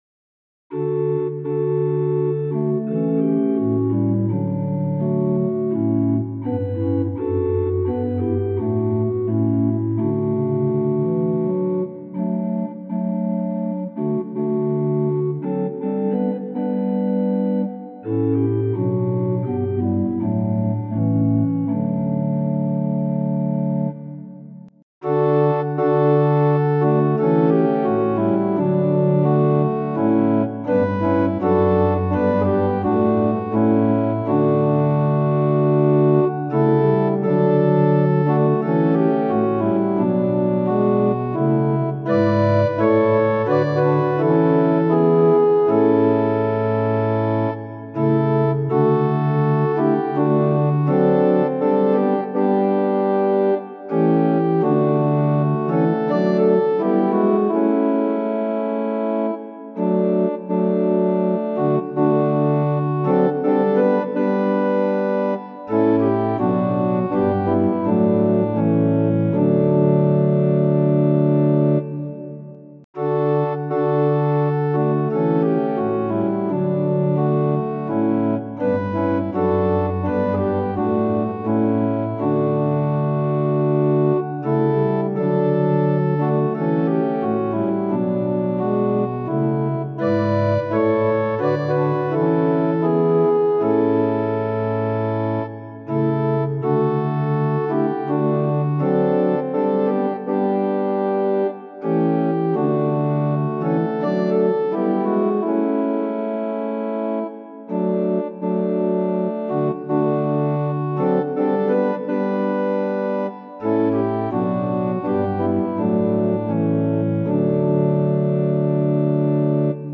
♪賛美用オルガン伴奏音源：
・柔らかい音色部分は前奏です
・はっきりした音色になったら歌い始めます
・節により音色が変わる場合があります
・間奏は含まれていません